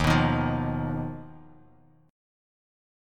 Ebsus2#5 chord